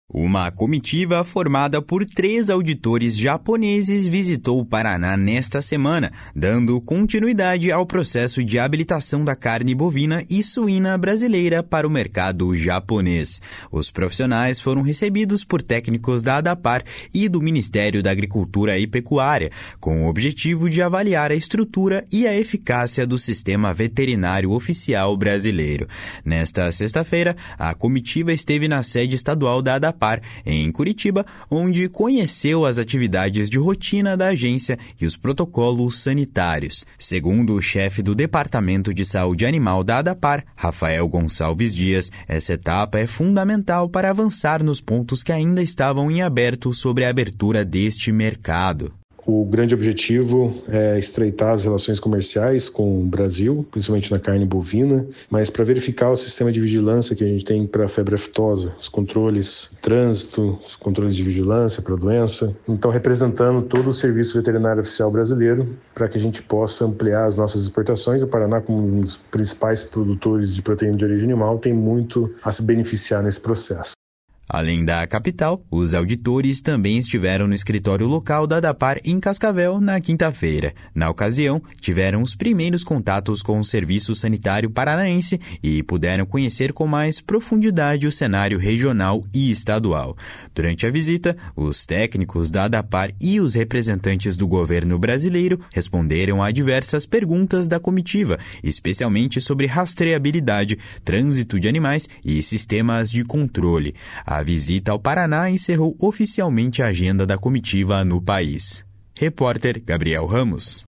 A visita ao Paraná encerrou oficialmente a agenda da comitiva no país. (Repórter